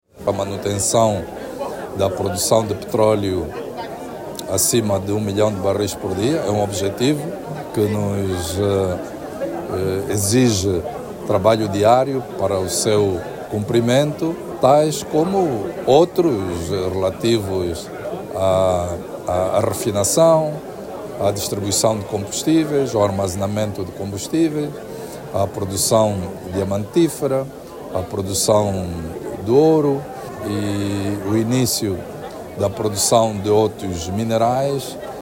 A garantia foi dada pelo ministro dos Recursos Minerais, Petróleo e Gás, Diamantino Azevedo, que falava ontem durante uma reunião do Conselho, onde reconheceu que a produção petrolífera continua a ser o principal desafio do sector.